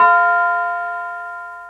Tubular Bell 2.wav